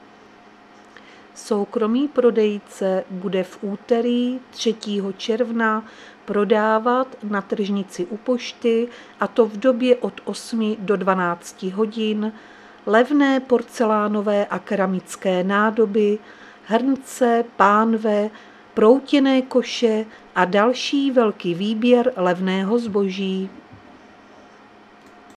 Záznam hlášení místního rozhlasu 2.6.2025